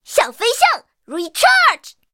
M4A3E2小飞象补给语音.OGG